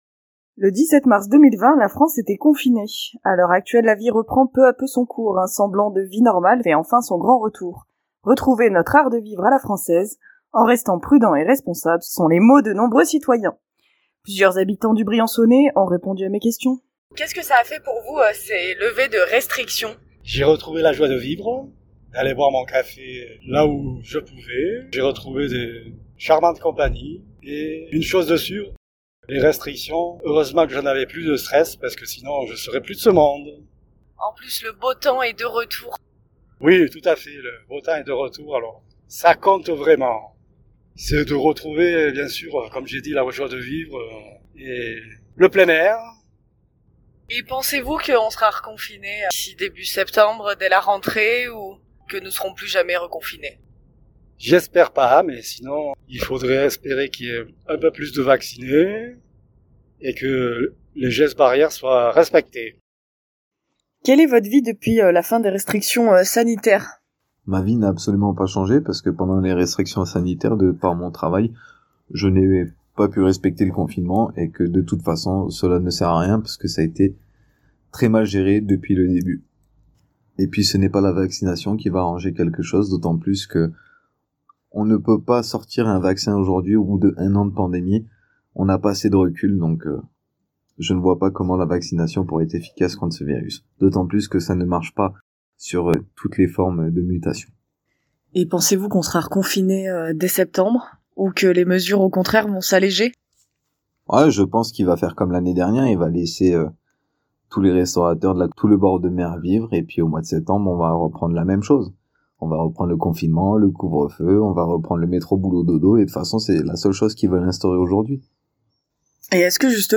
Plusieurs habitants du Briançonnais s'expriment